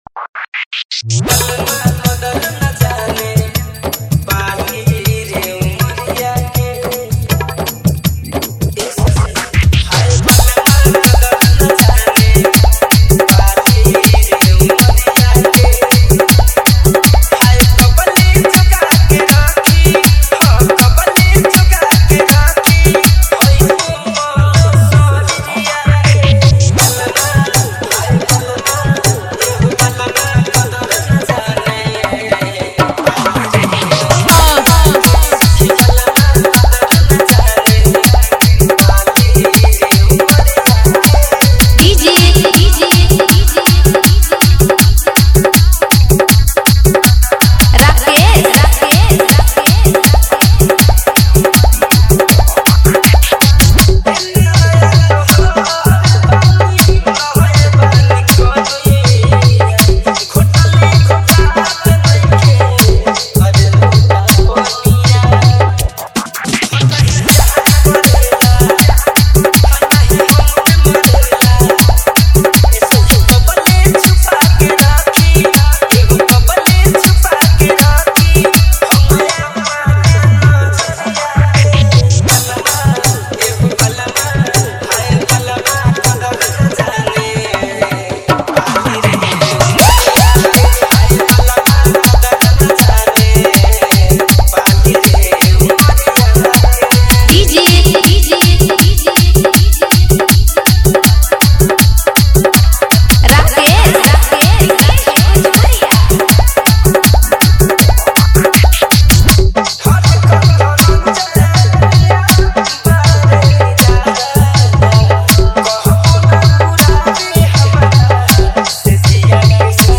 Bhojpuri Romantic DJ Remix